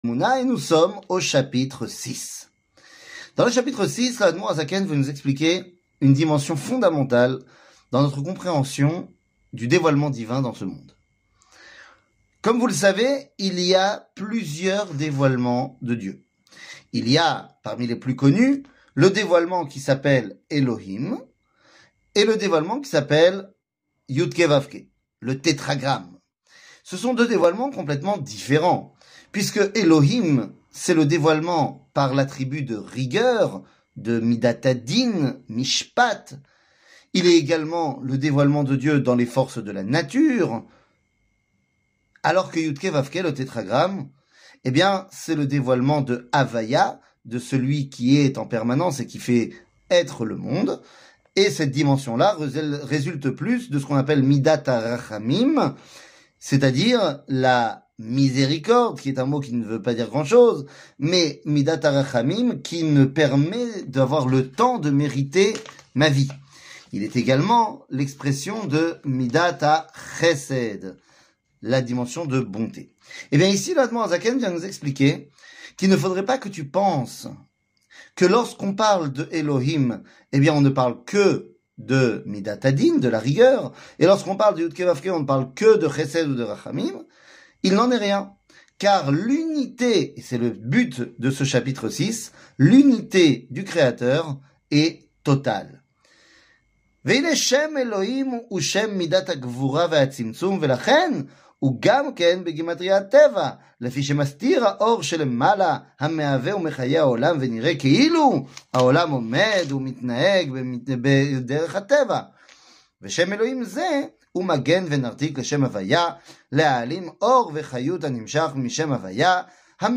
Tania, 60, Chaar Ayihoud Veaemouna, 6 00:08:07 Tania, 60, Chaar Ayihoud Veaemouna, 6 שיעור מ 09 אוגוסט 2023 08MIN הורדה בקובץ אודיו MP3 (7.42 Mo) הורדה בקובץ וידאו MP4 (20.83 Mo) TAGS : שיעורים קצרים